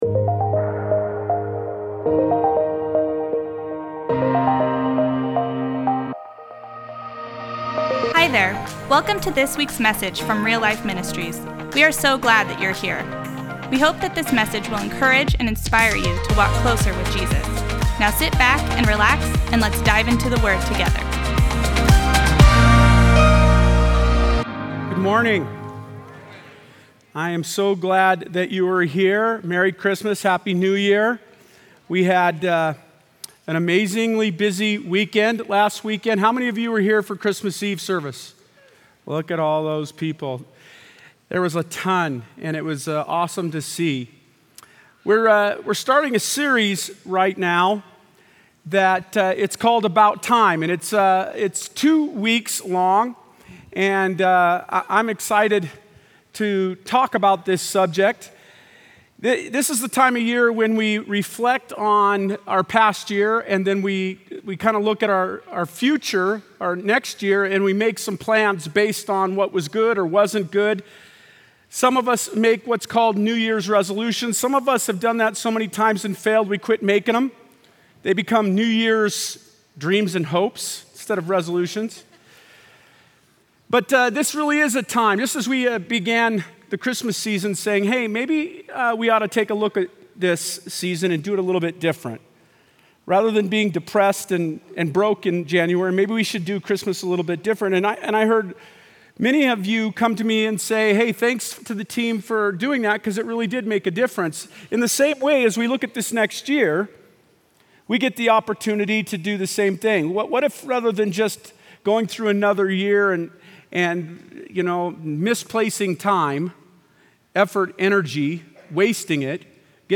Post Falls Campus